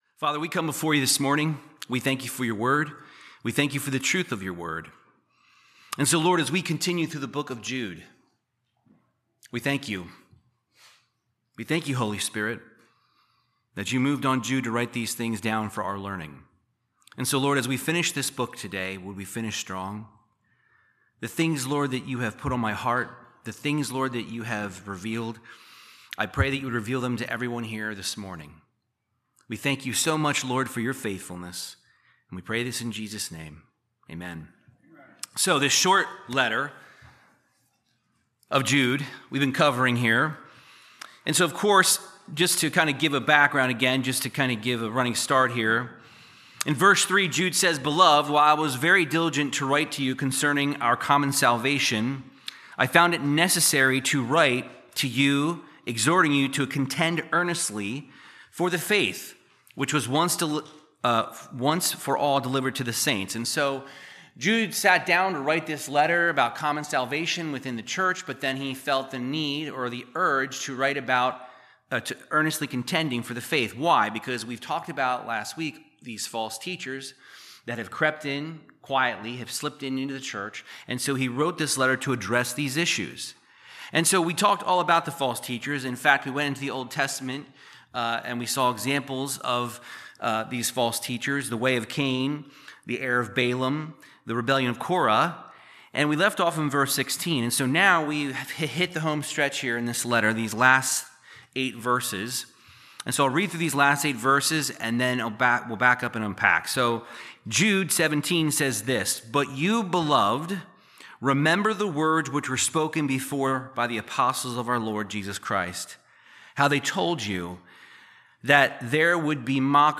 Verse by verse Bible Teaching through the book of Jude verses 17-25